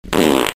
Loud Fart
loud-fart-soundbuttonsboard.net_.mp3